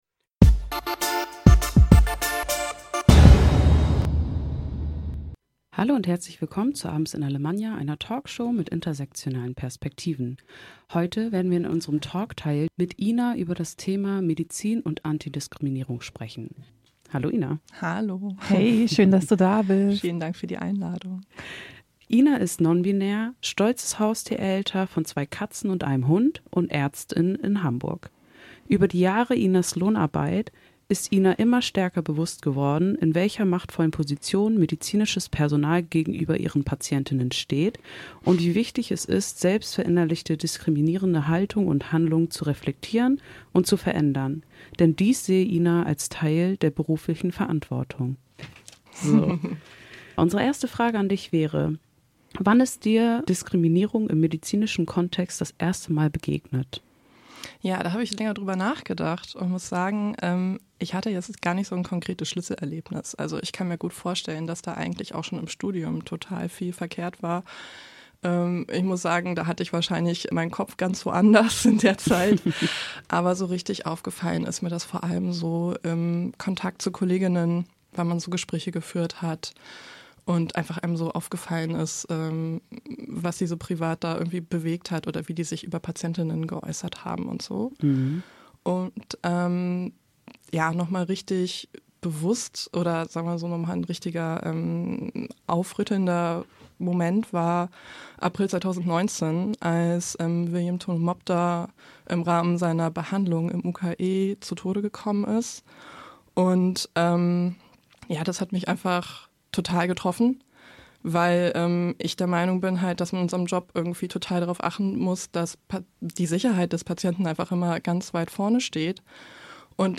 Eine Talkshow mit intersektionalen Perspektiven